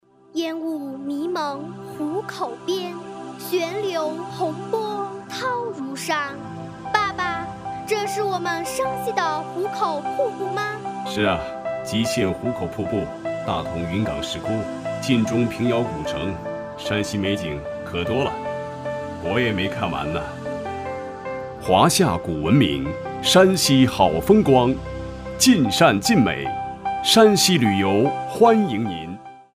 2013第五届大广赛 湖北省级E广播三等奖《美丽山西·晋善晋美